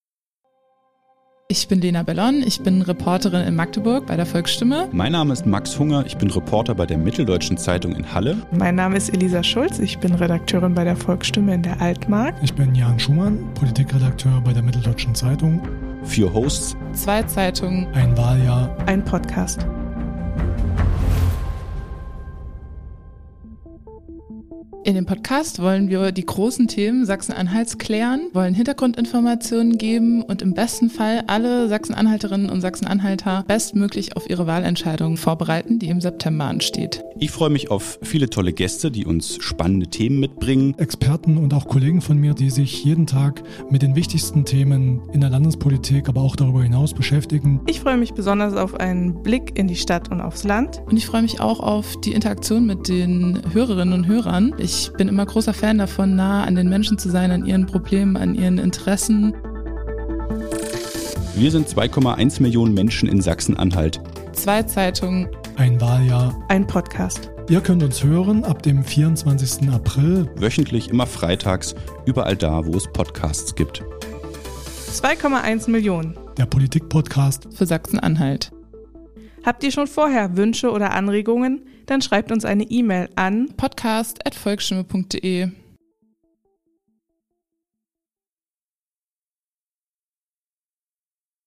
Trailer: vier Hosts, zwei Zeitungen, ein Wahljahr, ein Podcast
und stehen für einen sachlichen, authentischen Ton.